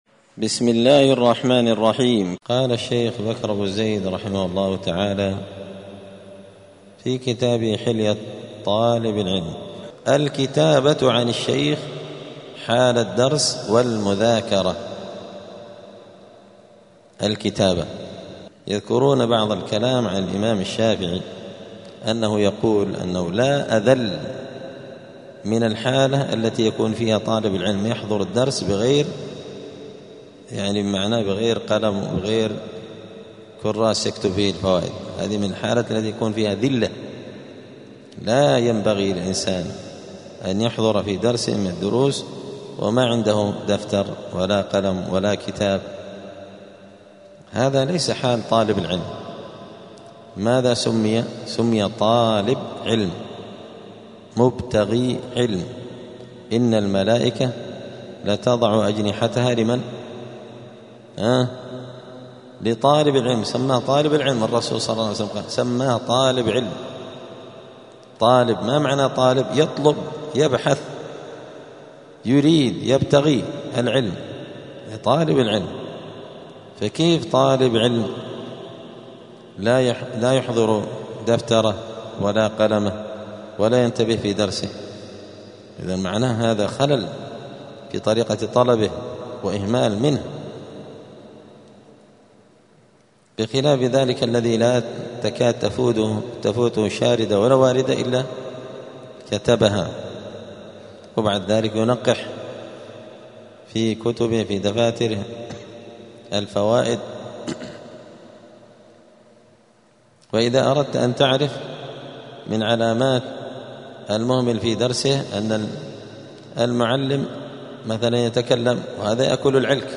*الدرس السادس والثلاثون (36) {فصل أدب الطالب مع شيخه الكتابة عن الشيخ حال الدرس والمذاكرة}*